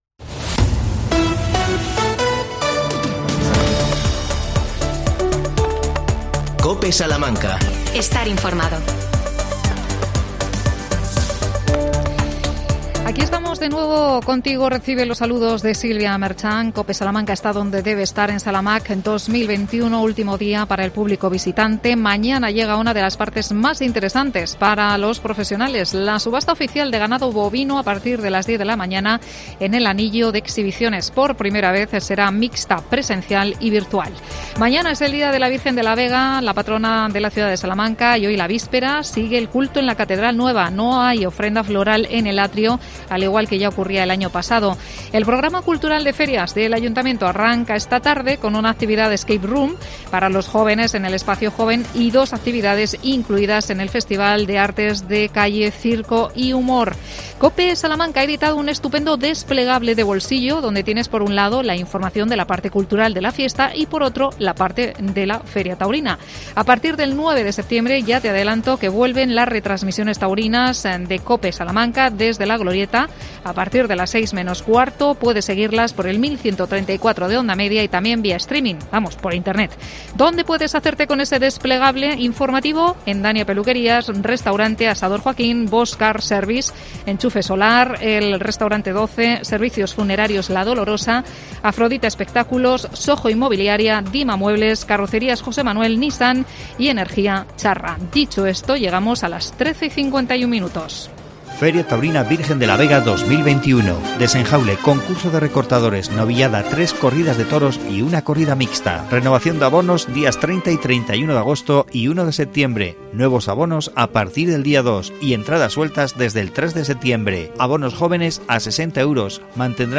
AUDIO: Desde el stand de la lenteja de la Armuña con representantes de la asociación Noroeste de Salamanca.